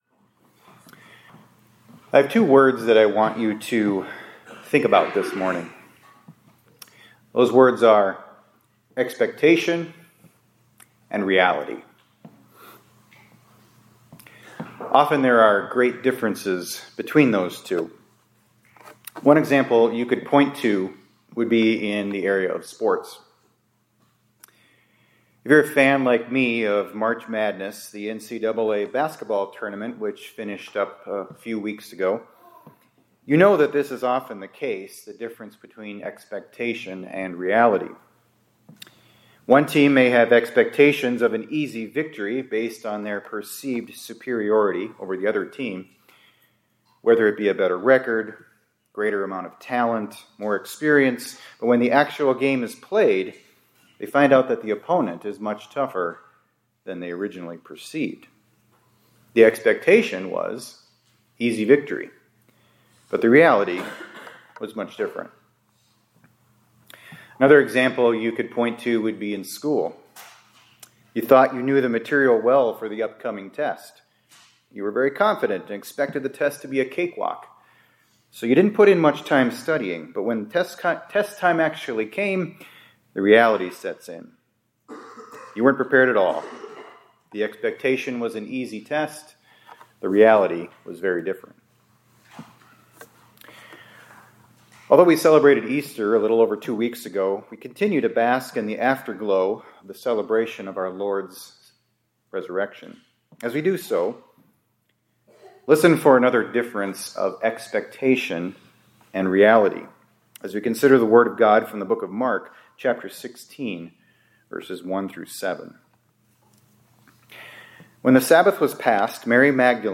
2026-04-22 ILC Chapel — Easter: When Reality Exceeded Expectation